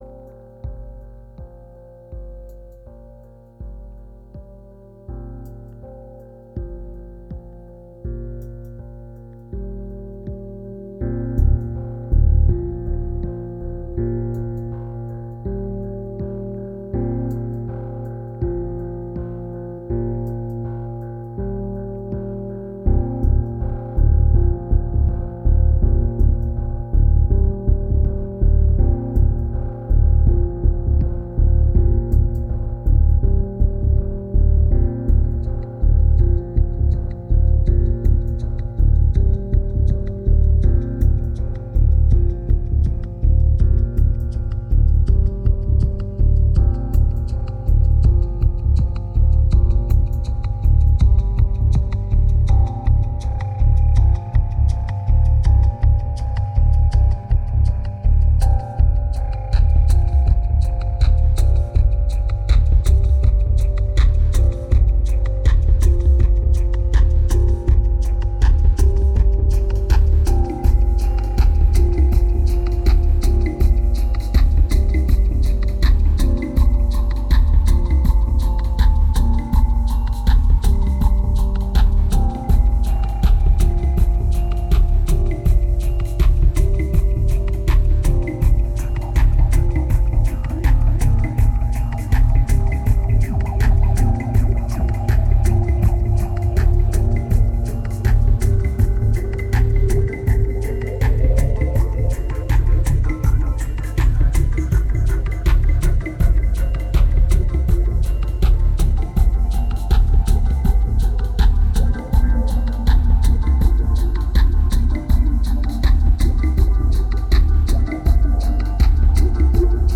2400📈 - -30%🤔 - 81BPM🔊 - 2010-11-24📅 - -248🌟